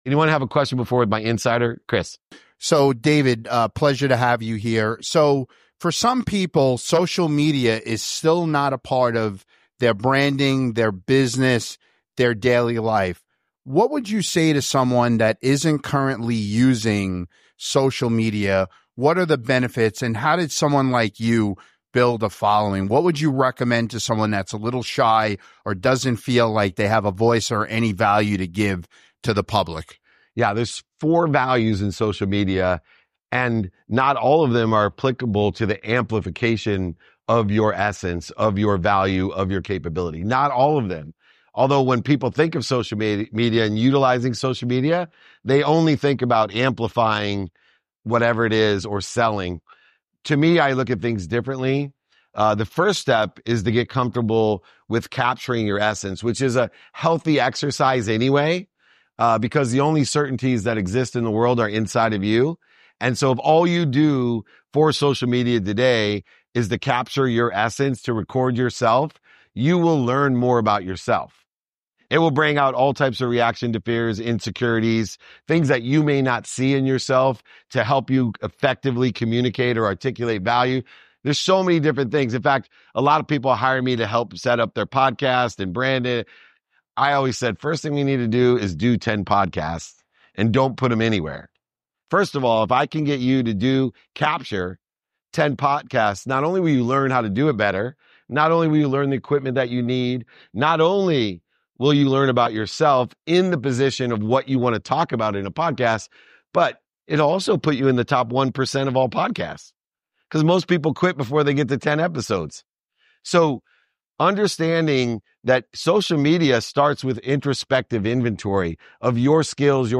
Today’s episode is from a live San Diego meetup, where I dive into the strategies behind authentic networking and building lasting connections.